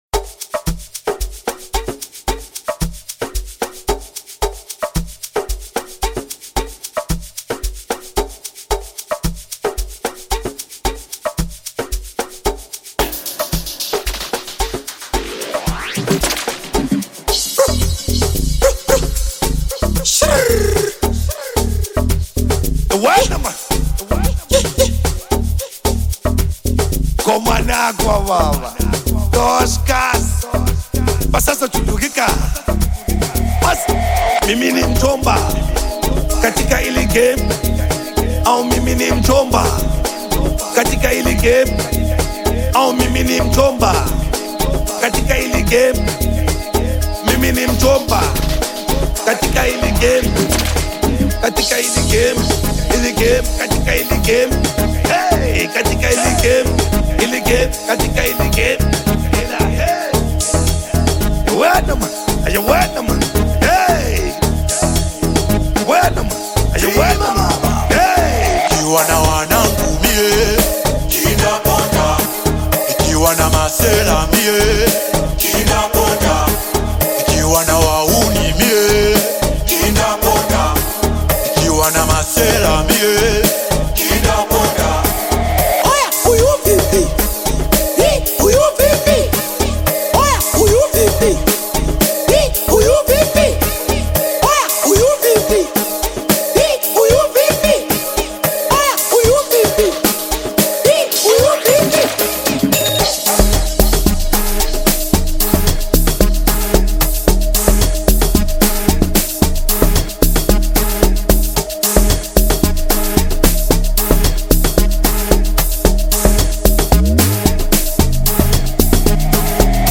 Tanzanian Artist, singer and songwriter
an Amapiano song
Bongo Flava